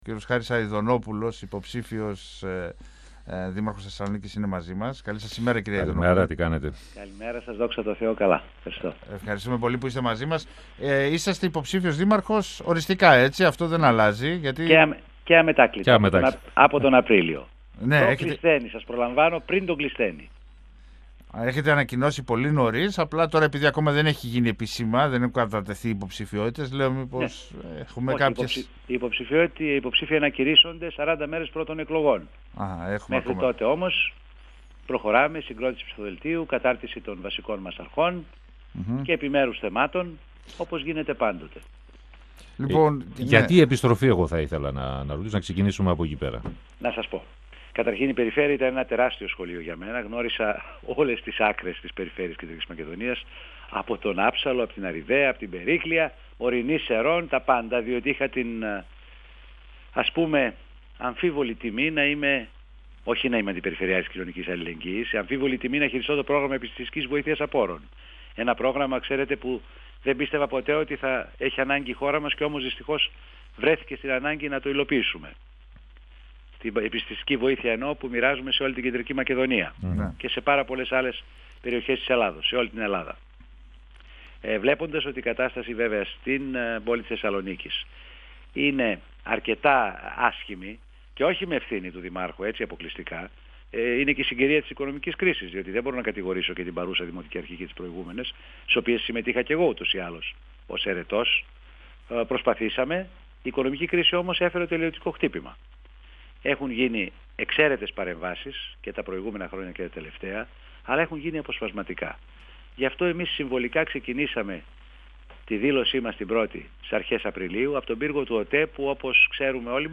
Ο υποψήφιος δήμαρχος Θεσσαλονίκης Χάρης Αηδονόπουλος, στον 102FM του Ρ.Σ.Μ. της ΕΡΤ3